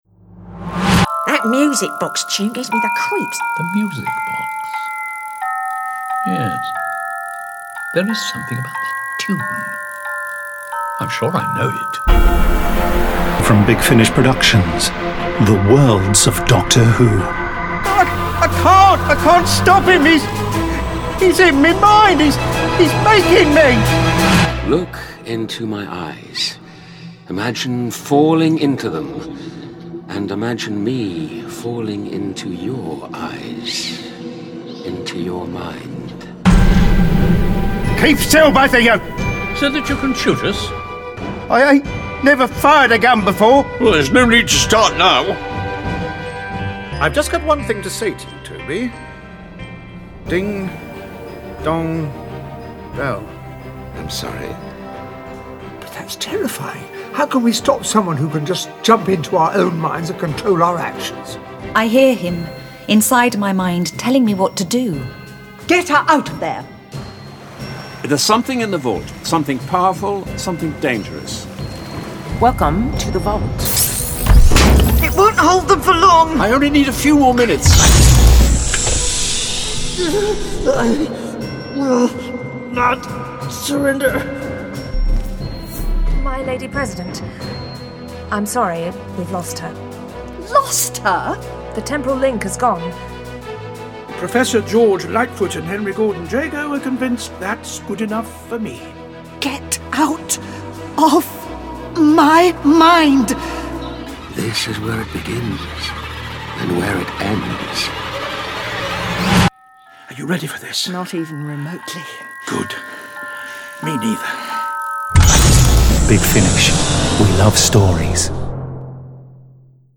Award-winning, full-cast original audio dramas
Starring Colin Baker Louise Jameson